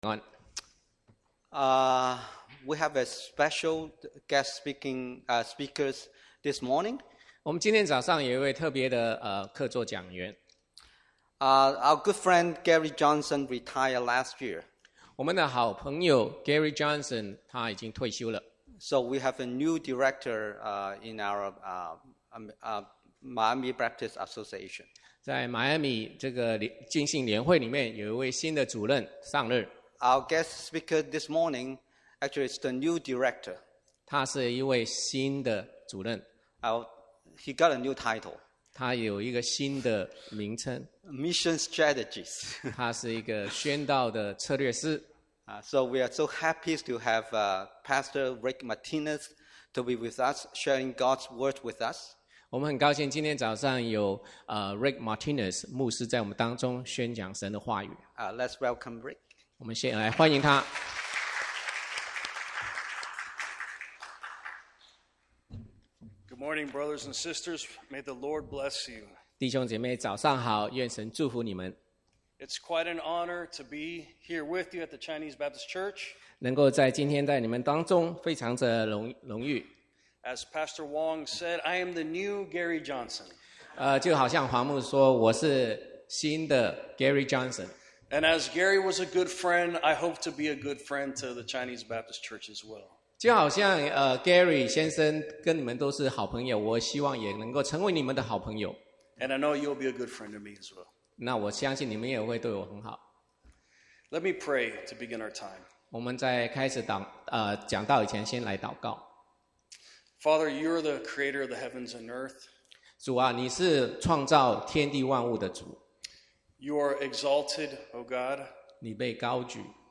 Past Sermons - Chinese Baptist Church of Miami